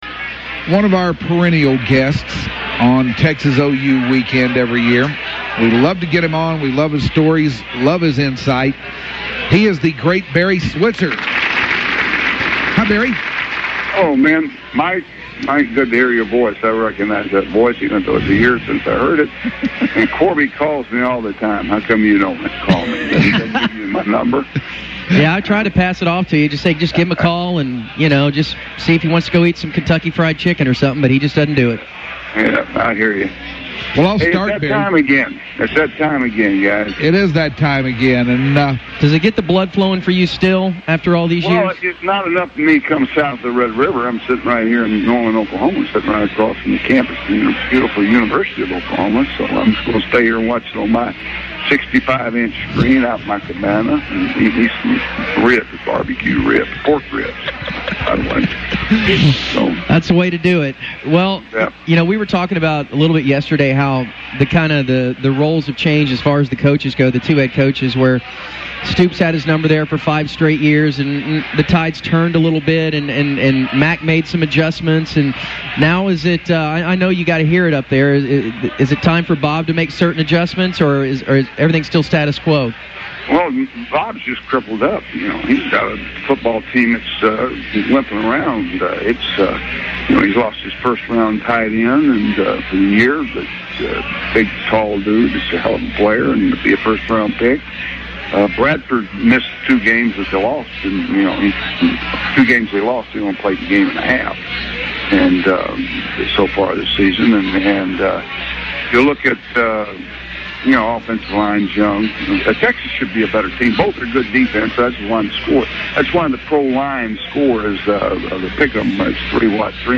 Barry Switzer Talks TX/OU Game - The UnTicket